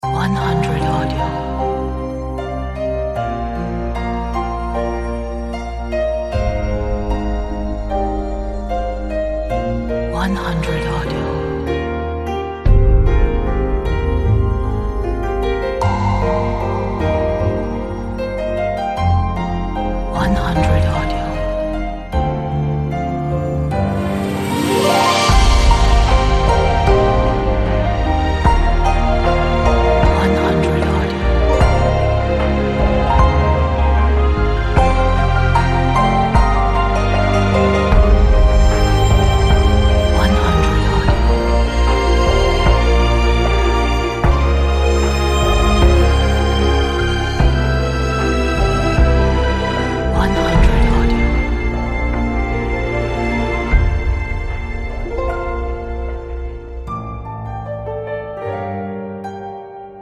跌宕起伏、荡气回肠的古筝、弦乐合奏曲。
标签：柔美 回忆 安静